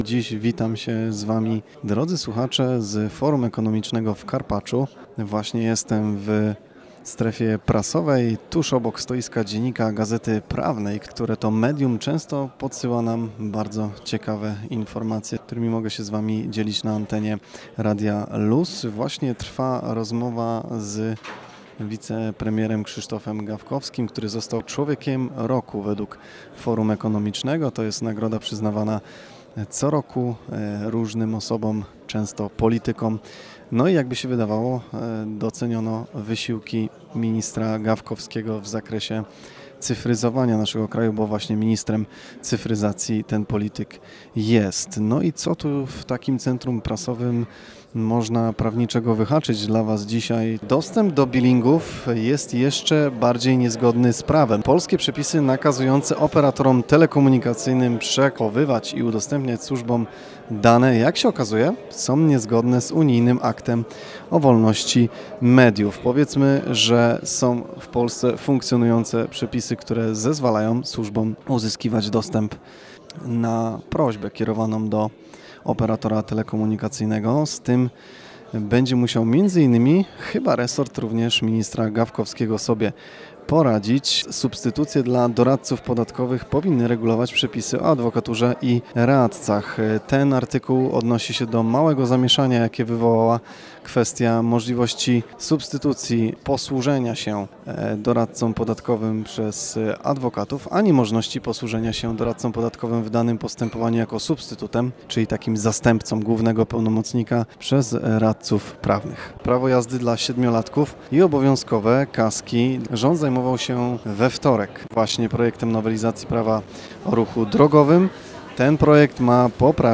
Prawniczy-przeglad-prasy-z-Forum-Ekonomicznego-2025.mp3